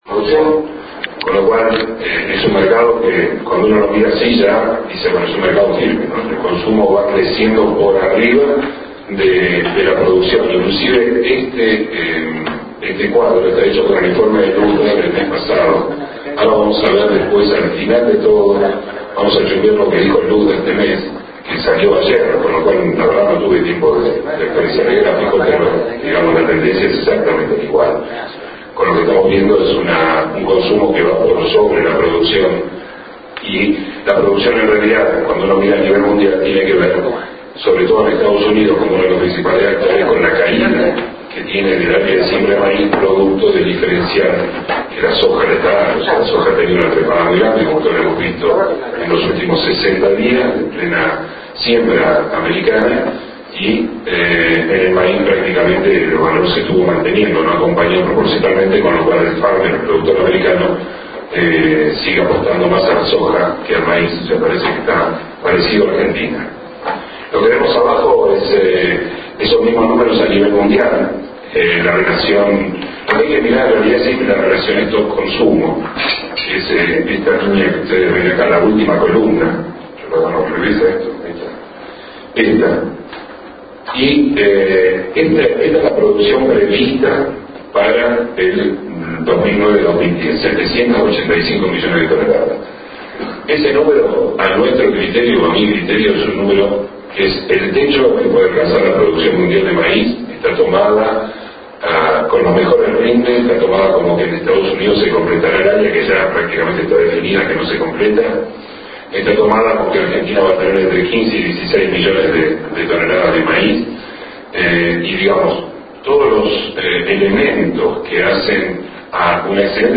Sepan disculpar los defectos de la misma ya que se hizo a través del sonido de ambiente.
Audio completo de la disertación